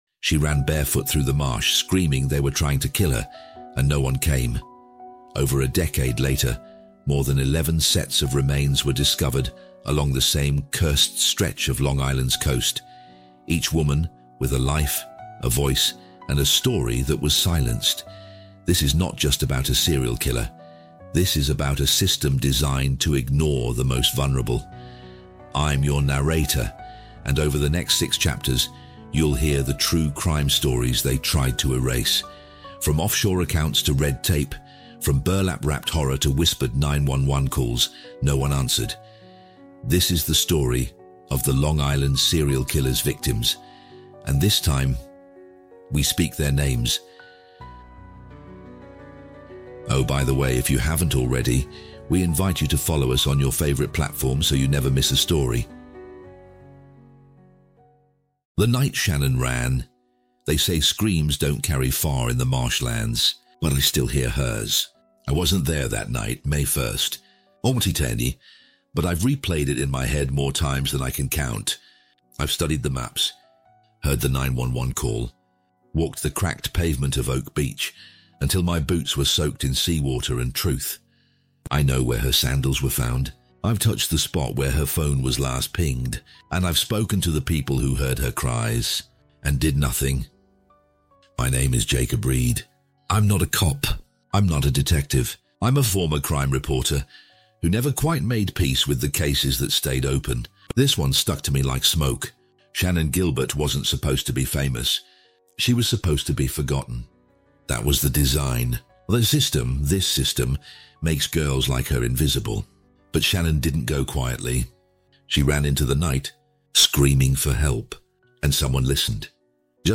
Chilling Details of the Long Island Serial Killer's Victims is a six-part, emotionally immersive true crime audio series that grips you by the throat and never lets go. Told through the raw first-person perspective of an investigative journalist, this haunting journey peels back the darkness surrounding one of America’s most disturbing unsolved murder cases.